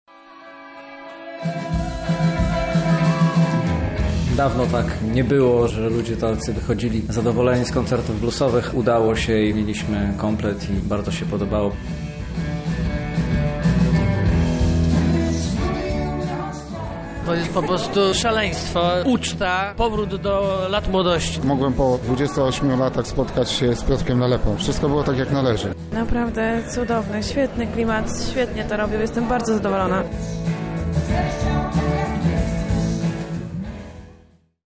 Dźwięki takie usłyszeliśmy dzięki V edycji Chatka Blues Festival .
sami widzowie